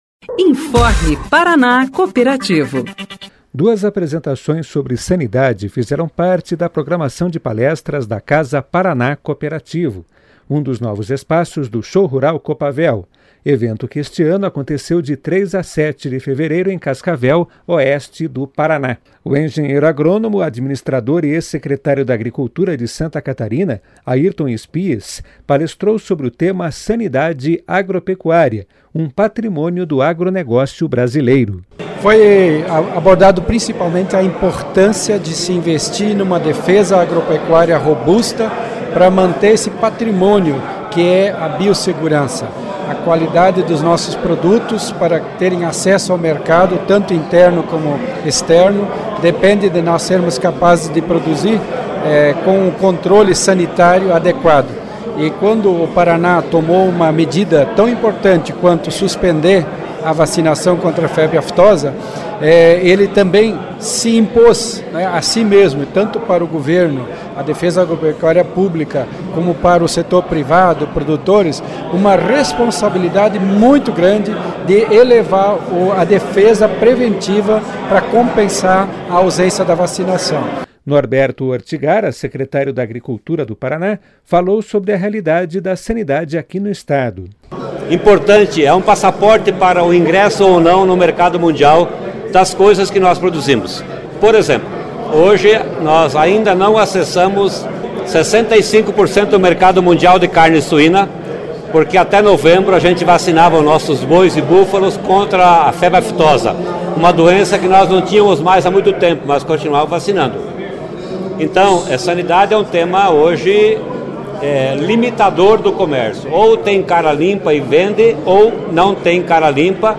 O tema 'sanidade' abriu a série de palestras na Casa Paraná Cooperativo, um dos novos espaços do Show Rural Coopavel, evento realizado de 3 a 7 de fevereiro, em Cascavel, no oeste do Estado. O engenheiro agrônomo, administrador e ex-secretário da agricultura de Santa Catarina, Airton Spies, palestrou sobre o tema "Sanidade Agropecuária: Um Patrimônio do Agronegócio Brasileiro". Ele focou na importância de investir num trabalho de defesa agropecuária para manter esse patrimônio, que é a biosegurança.